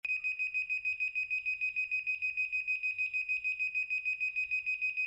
• Der Vario-Ton variiert in Frequenz und Tonhöhe je nach vertikaler Geschwindigkeit
Vertikalgeschwindigkeit Piep-Ton